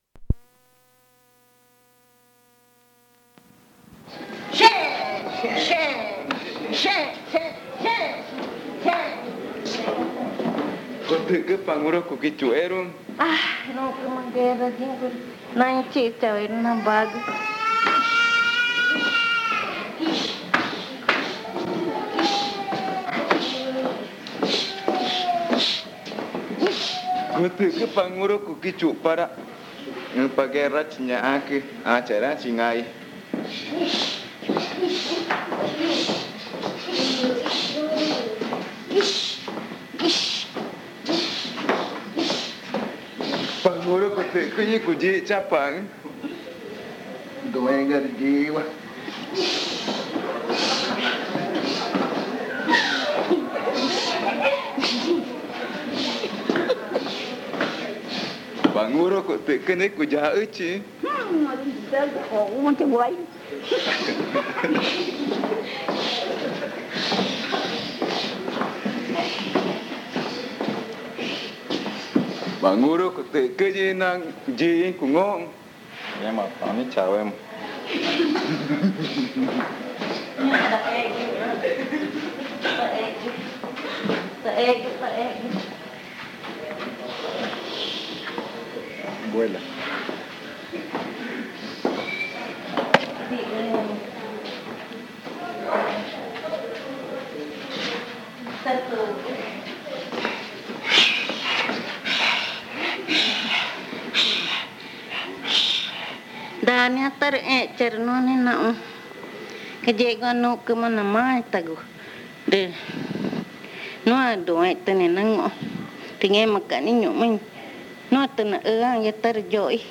Casete con cantos y juegos tradicionales magütá
en un taller realizado en la Comunidad Indígena de Tipisca. Son varios los y las participantes magütá que compartieron sus cantos y juegos.